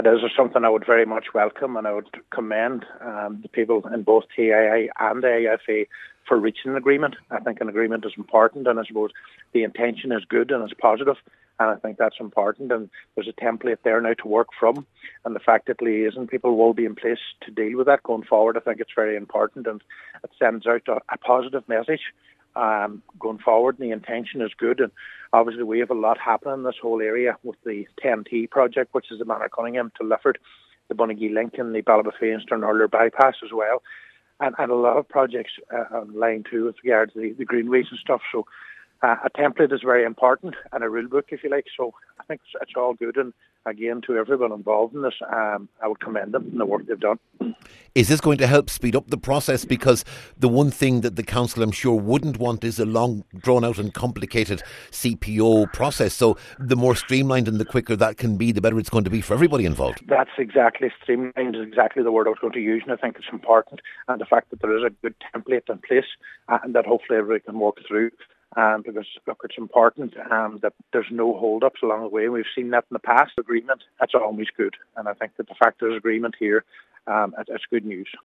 Cllr Ciaran Brogan says anything that streamlines the process is to be welcomed, and this deal will be to the benefit of all concerned………….